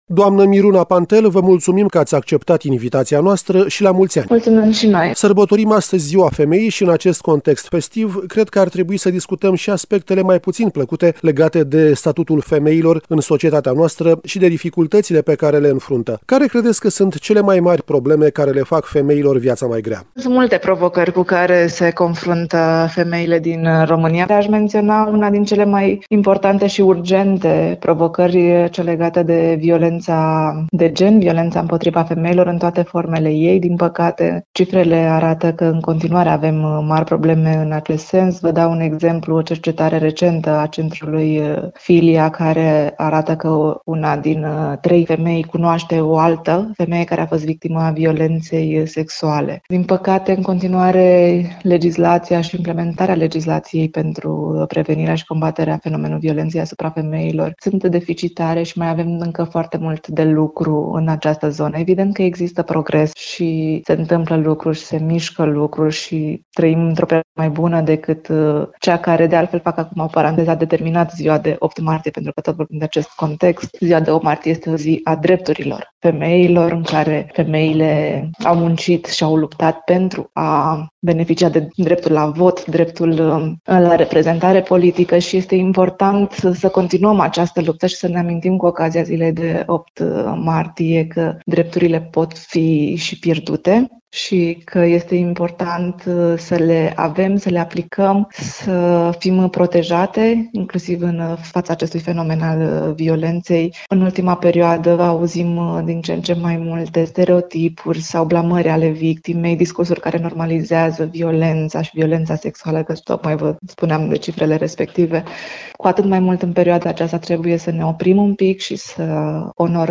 în interviul următor